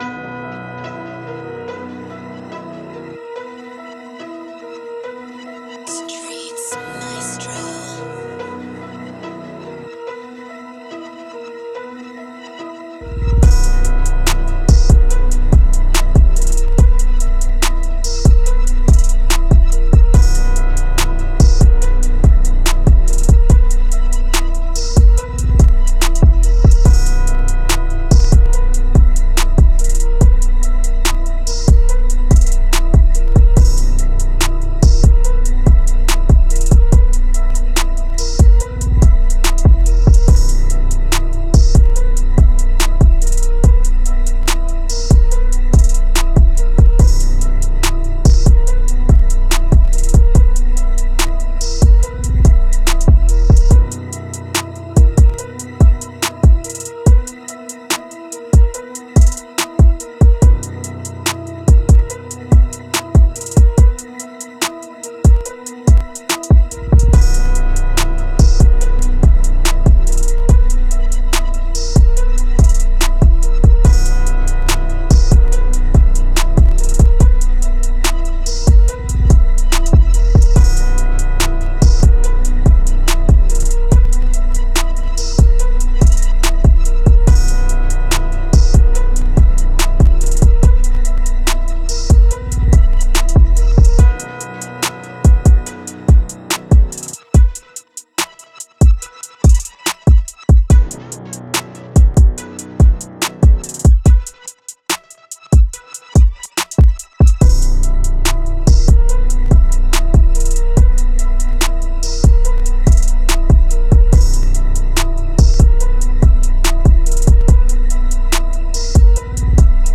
Moods: hard, dark, dramatic
Genre: Rap
Tempo: 143